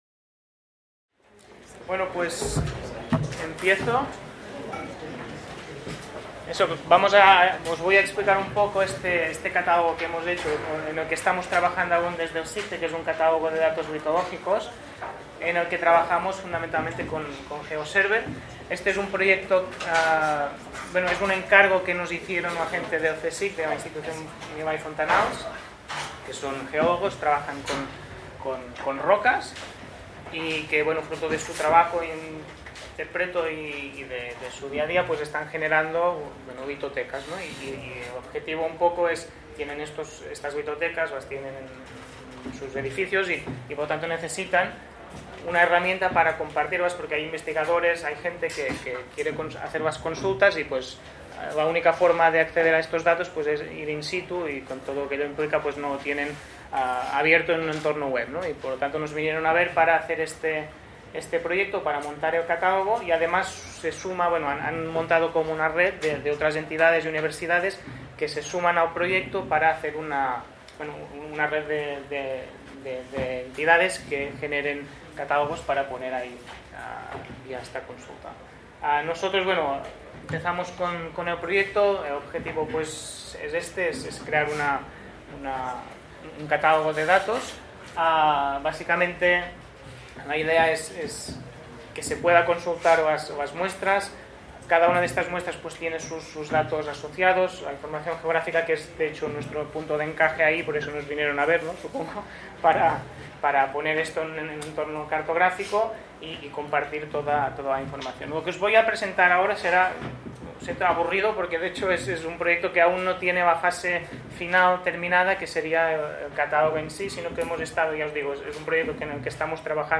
En el marc de les 18enes Jornades de SIG Lliure 2025.